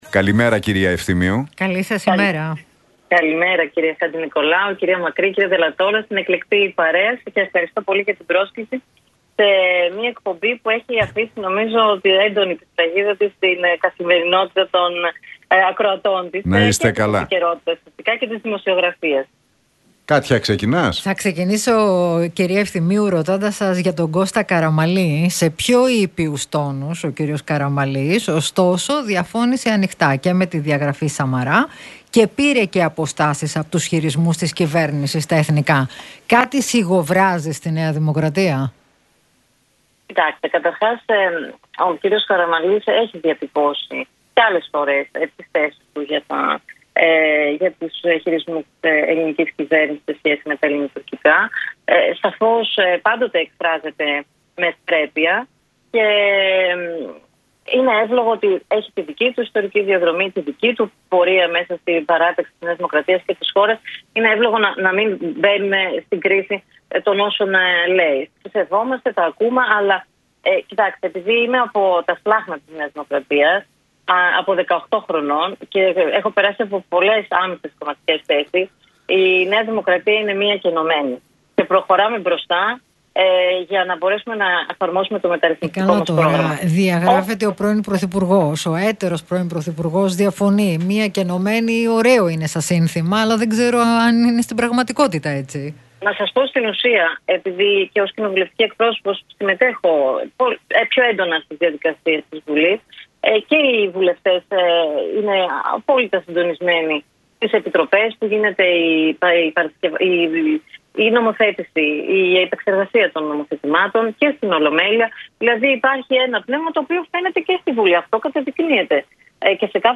Άννα Ευθυμίου στον Realfm 97,8: Η ΝΔ είναι μία και ενωμένη και προχωράμε μπροστά – Τι είπε για τη δημοσκοπική υποχώρηση